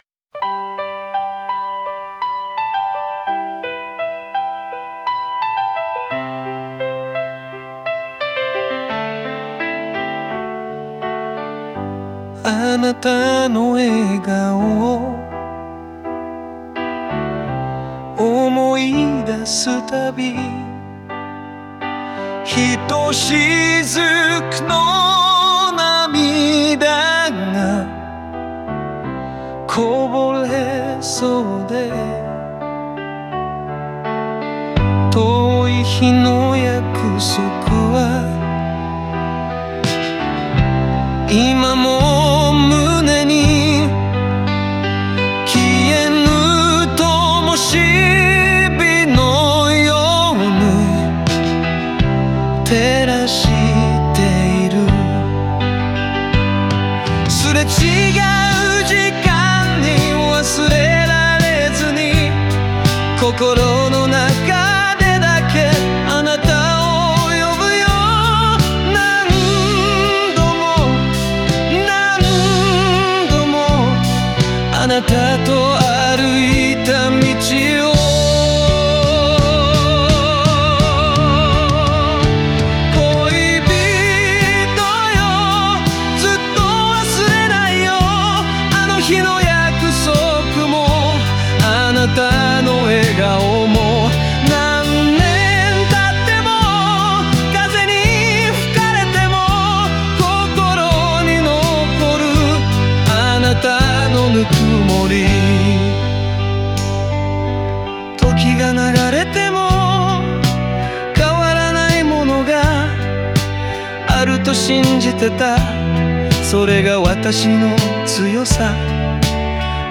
もう一度会えたならという切ない願望が込められ、曲が静かに締めくくられます。